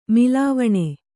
♪ milāvaṇe